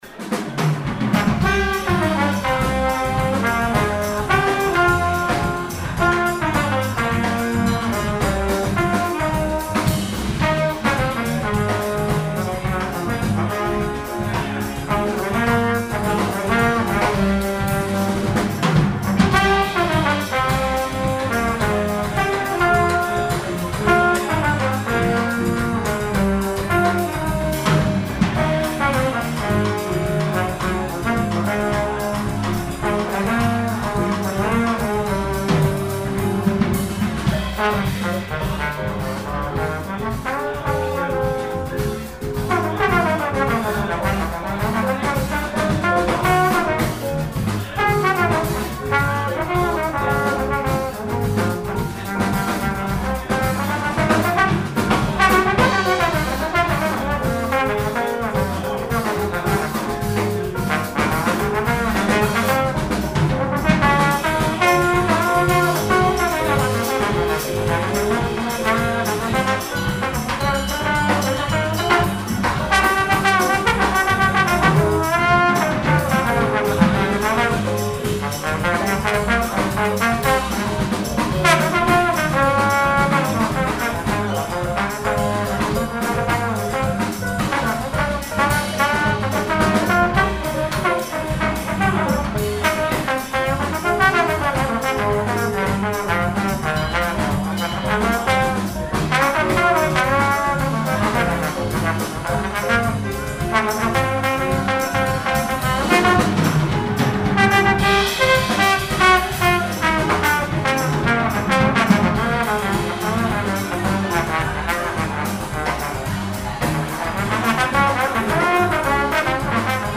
Stilrichtungen: Blues, Bossa, Bebop, Balladen ...wie